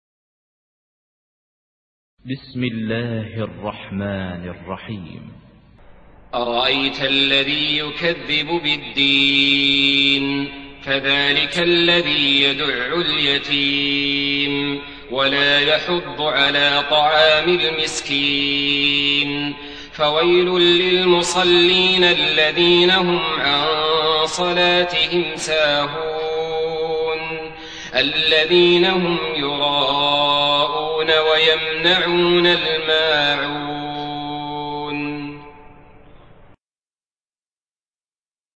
Surah Maun MP3 by Saleh Al-Talib in Hafs An Asim narration.
Murattal Hafs An Asim